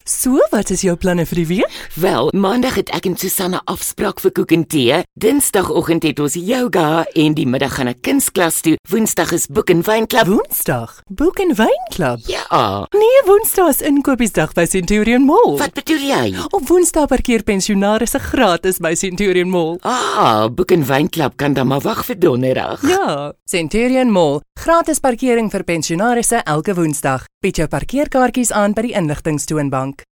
AF RS COM 01 Commercials Female Afrikaans